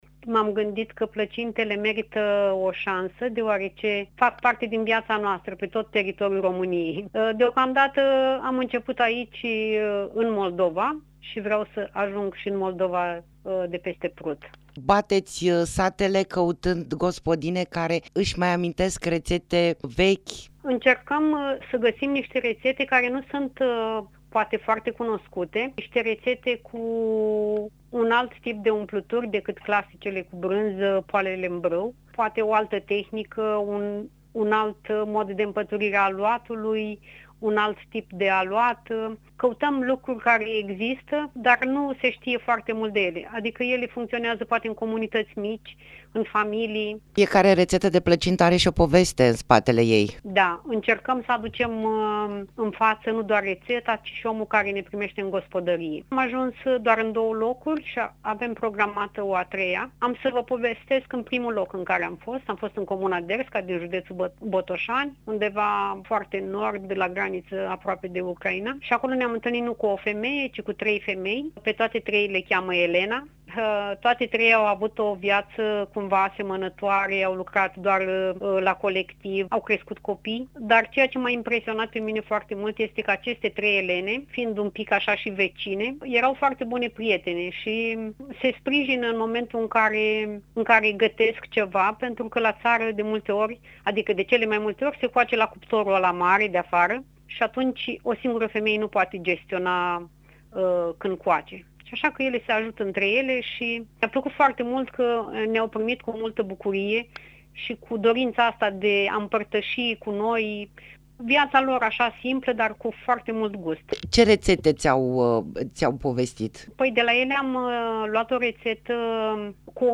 Interviu-placinte.mp3